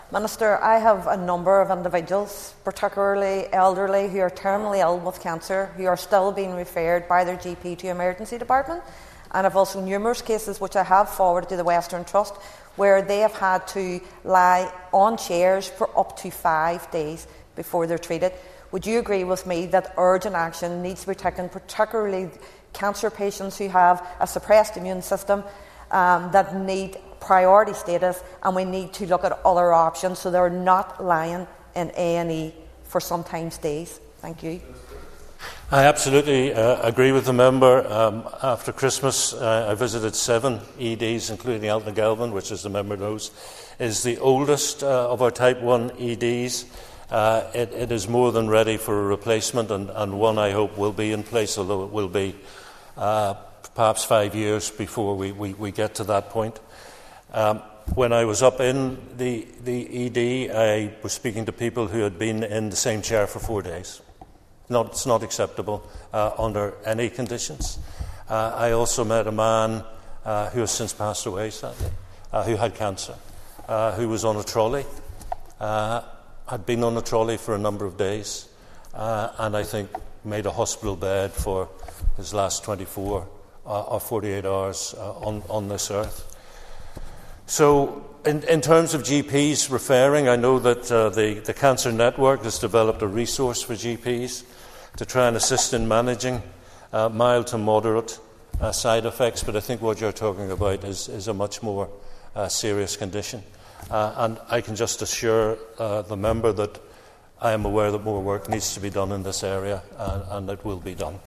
He was responding on the Assembly floor to Foyle MLA Ciara Ferguson, who told Minister Mike Nesbitt that some elderly patients with cancer were being referred to the hospital’s ED, only to be left on a trolley or chair for 24 hours or more.
Minister Nesbitt recounted his own experience when he visited the hospital a number of weeks ago: